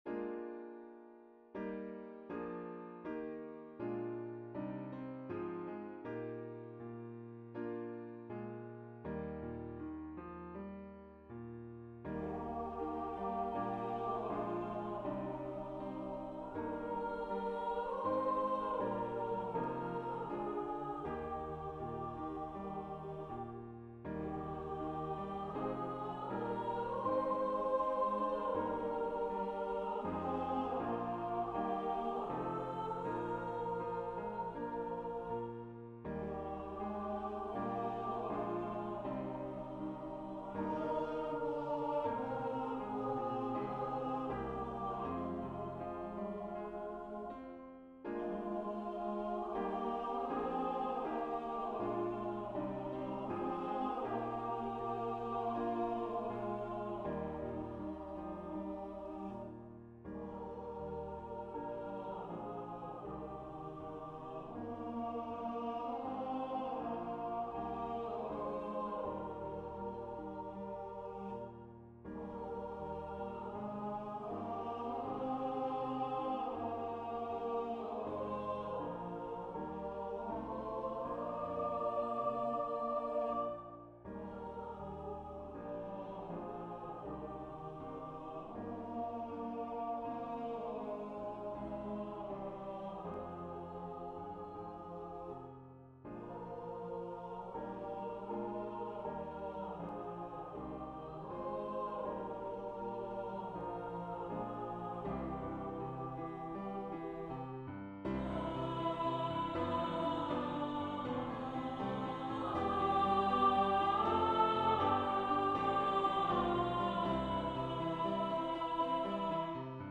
Duet, ST
Voicing/Instrumentation: ST , Duet We also have other 63 arrangements of " Abide With Me ".